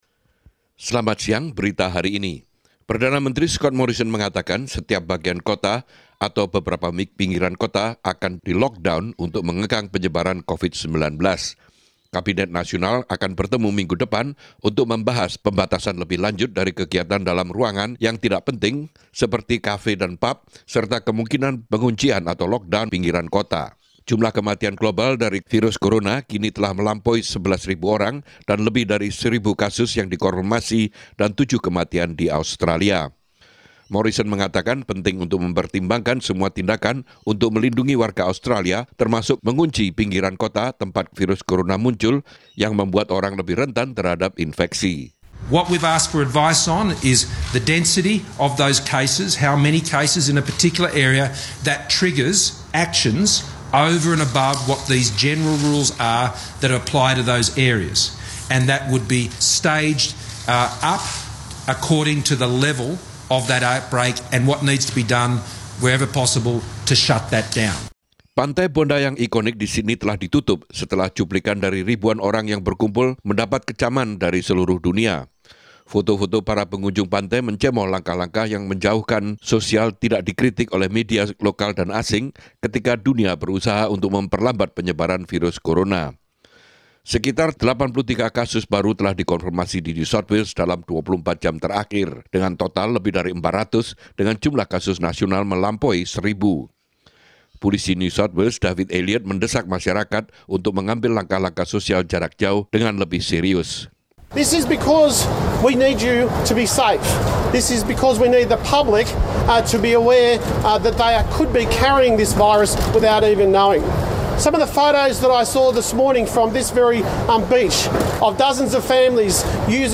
SBS Radio News in Indonesian - News Builletin 21 Maret 2020